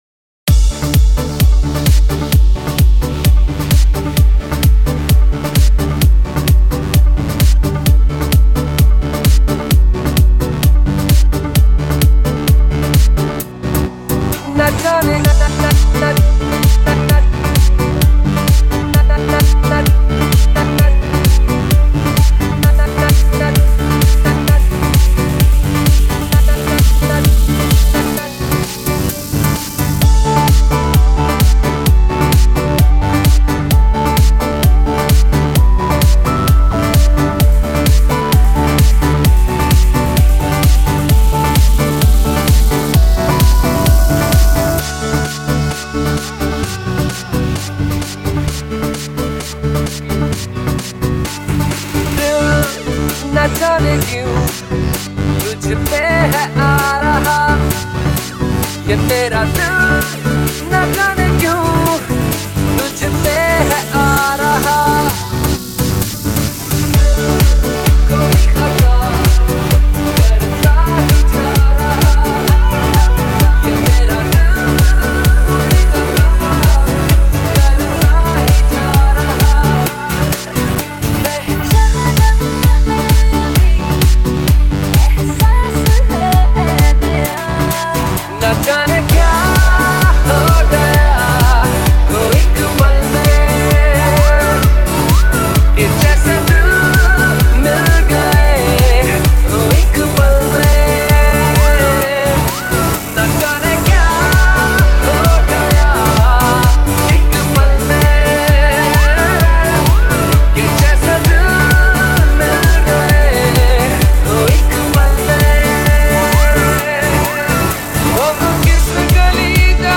HomeMp3 Audio Songs > Others > Latest DJ-Mixes (March 2013)